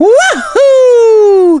One of three voice clips from Mario in Super Mario Galaxy.
SMG_Mario_Wahoo_(flung).wav